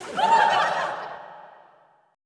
LAUGHTER 1-L.wav